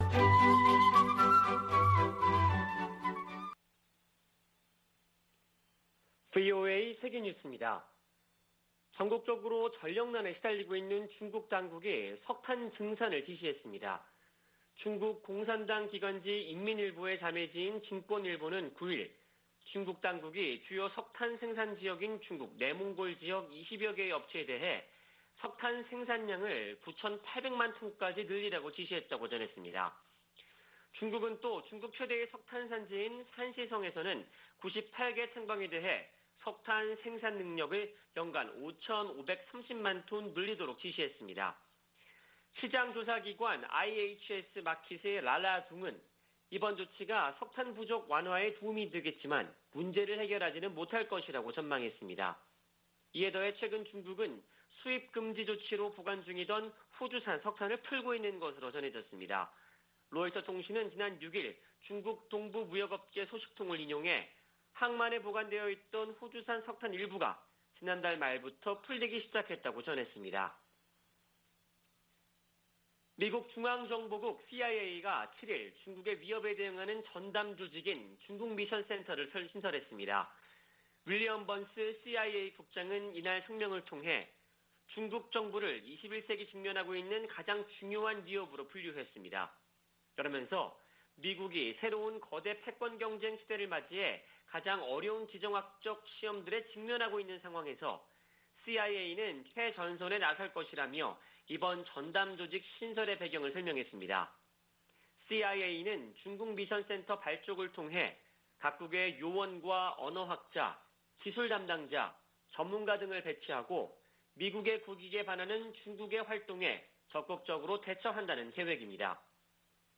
VOA 한국어 아침 뉴스 프로그램 '워싱턴 뉴스 광장' 2021년 10월 9일 방송입니다. 코로나 방역 지원 물품이 북한에 도착해, 남포항에서 격리 중이라고 세계보건기구(WHO)가 밝혔습니다. 대북 인도적 지원은 정치 상황과 별개 사안이라고 미 국무부가 강조했습니다. 북한에서 장기적인 코로나 대응 규제 조치로 인권 상황이 더 나빠졌다고 유엔 북한인권 특별보고관이 총회에 제출한 보고서에 명시했습니다.